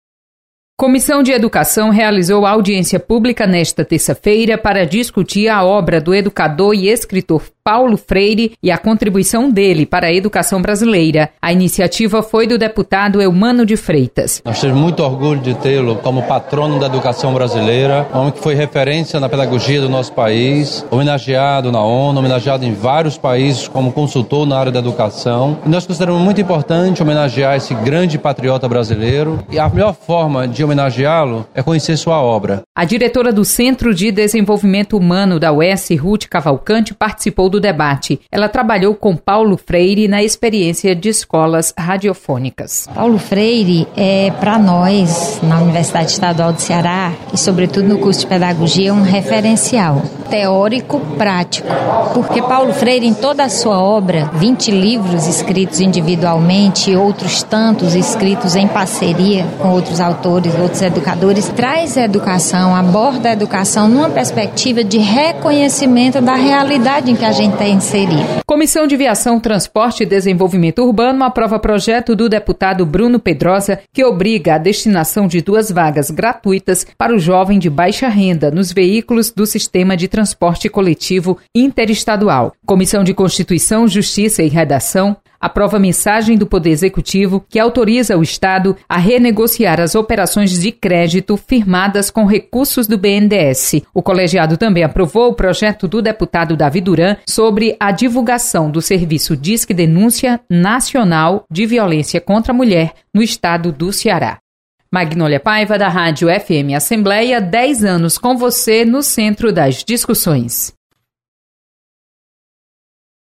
FM Assembleia